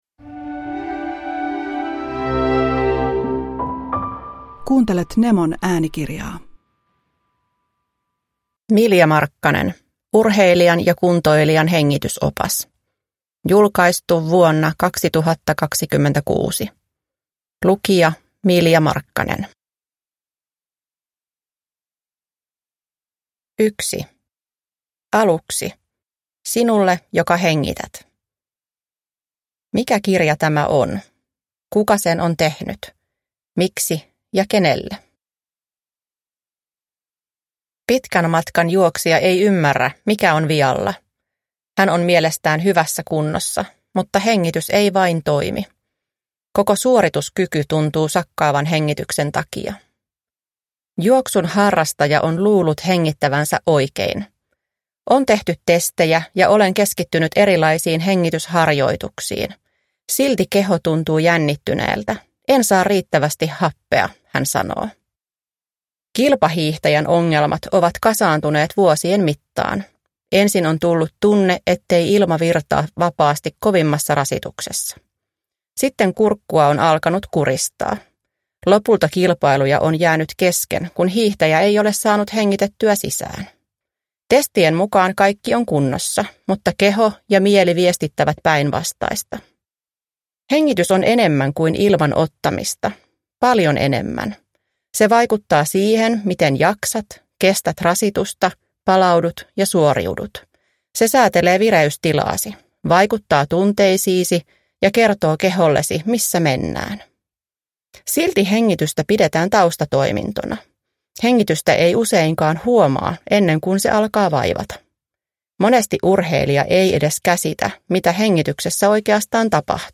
Urheilijan ja kuntoilijan hengitysopas – Ljudbok